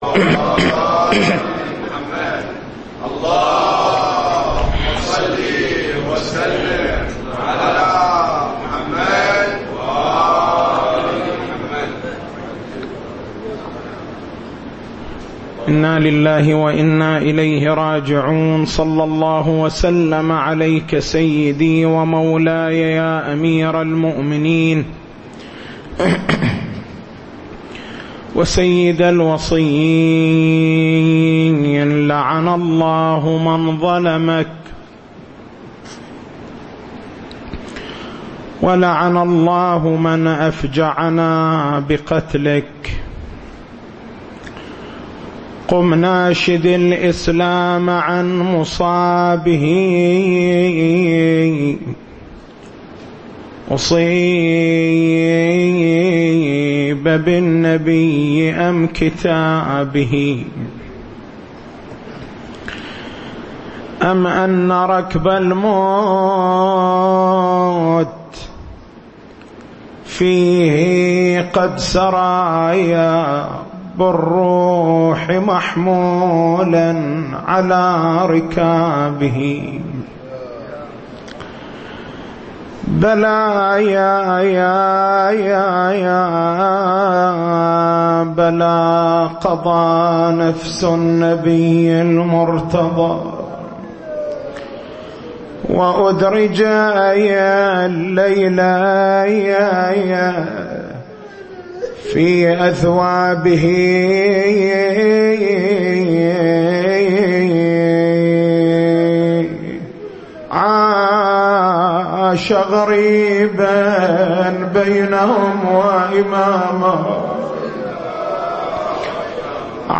تاريخ المحاضرة: 22/09/1436 نقاط البحث: كيفيّة الاستدلال بآية {وقفوهم إنّهم مسؤولون} على إمامة أمير المؤمنين (ع) بيان وجه التوفيق بين الآيات المثبتة للمساءلة والآيات النافية لها التسجيل الصوتي: اليوتيوب: شبكة الضياء > مكتبة المحاضرات > شهر رمضان المبارك > 1436